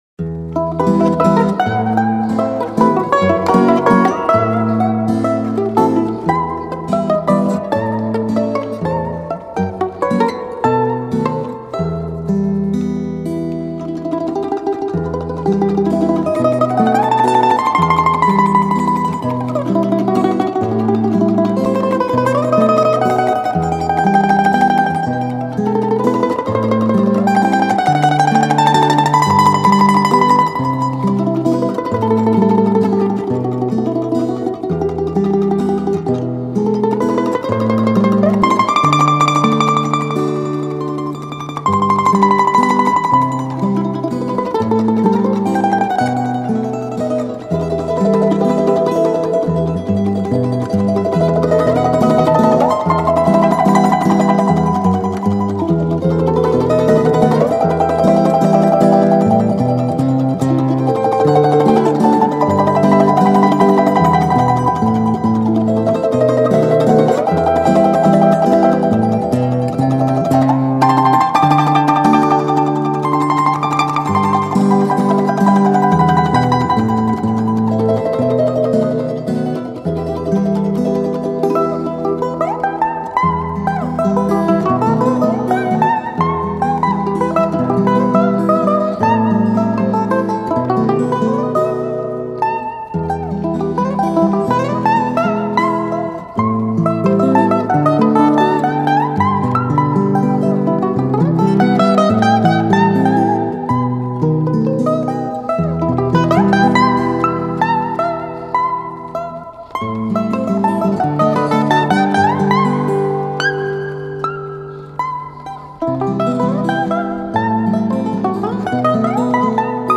Балалайка